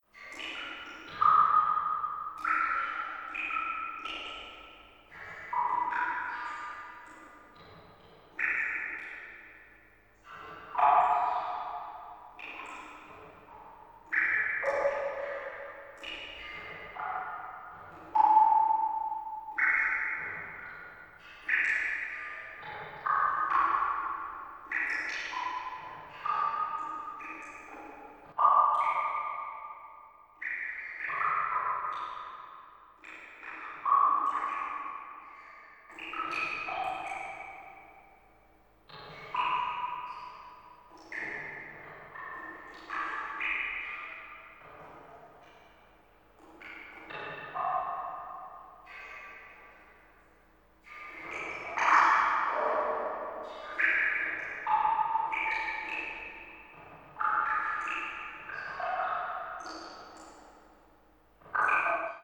water-drops.ogg